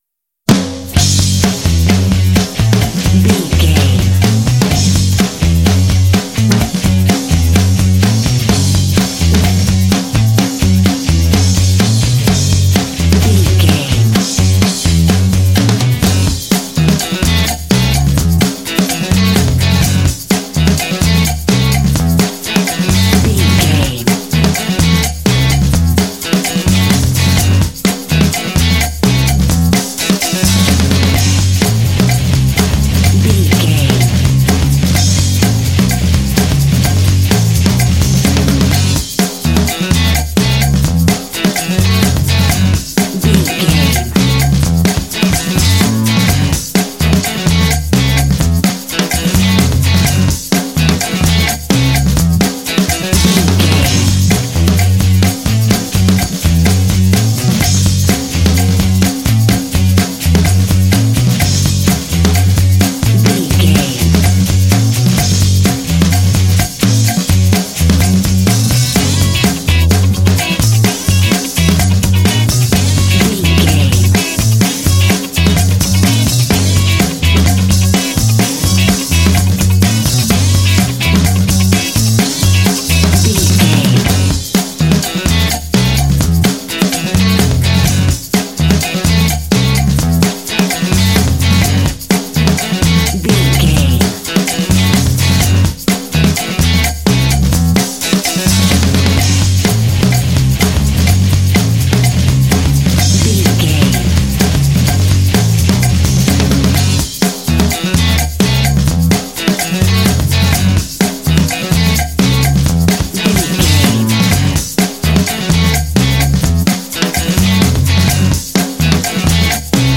Uplifting
Aeolian/Minor
F#
powerful
energetic
groovy
horns
brass
drums
electric guitar
bass guitar